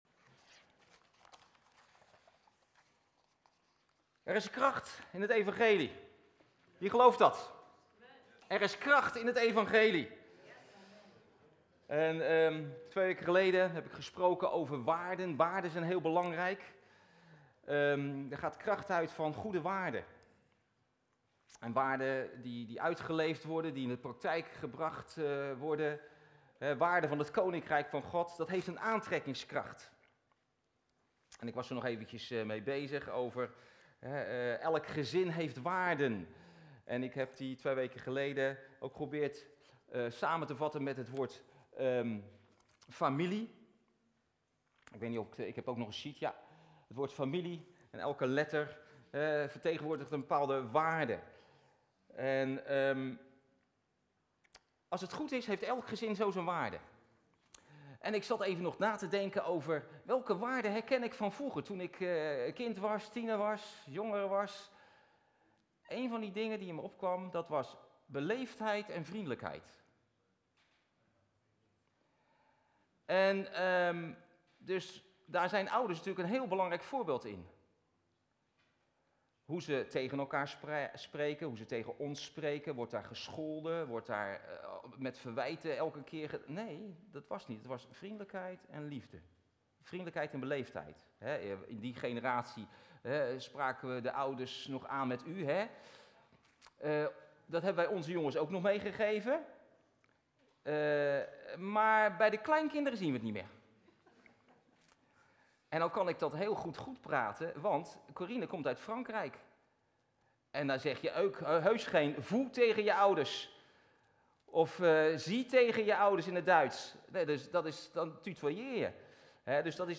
Doopdienst 2 november 2025